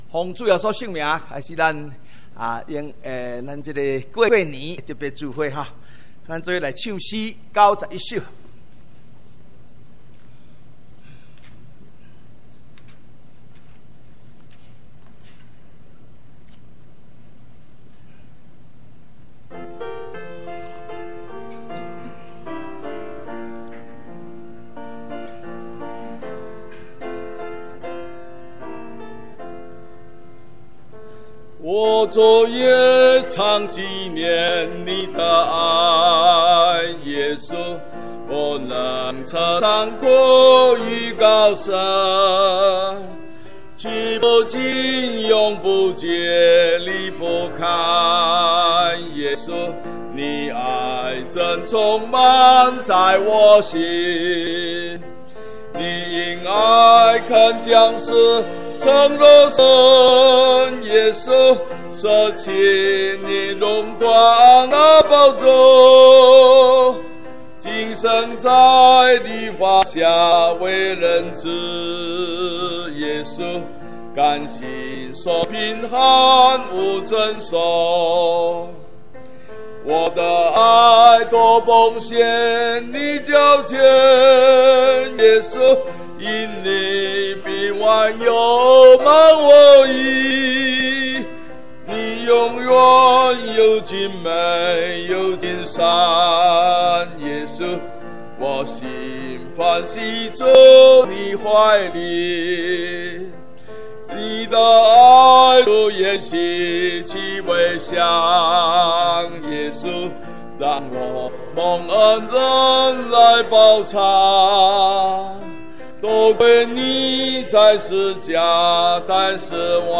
除夕特別聚會：